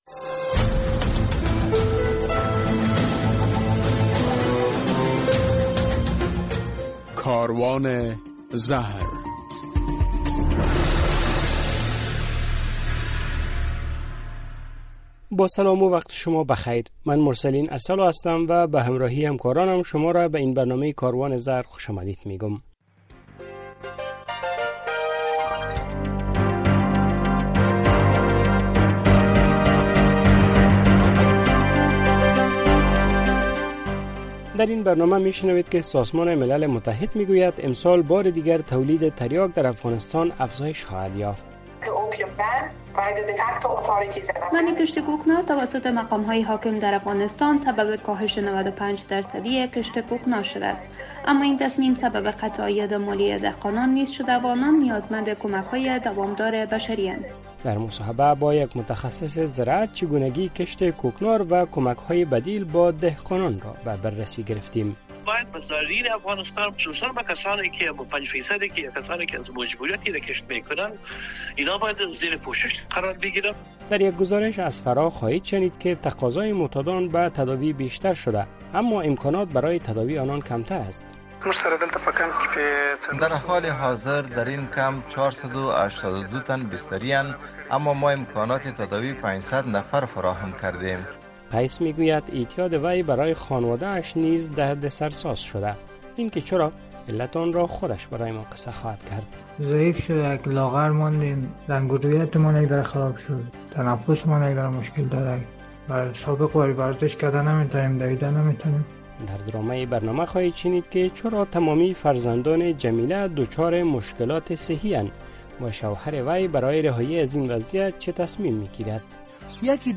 در برنامه این هفته کاروان زهر می‌شنوید که سازمان ملل متحد می‌گوید امسال بار دیگر تریاک بیشتر در افغانستان تولید خواهد شد، در رابطه به علت ادامه کشت کوکنار و موثریت مبارزه طالبان با آن با یک تحلیل‌گر مصاحبه کردیم، در یک گزارش از فراه خواهید شنید که تقاضا معتادان به تداوی بیشتر اما امکانات بستری...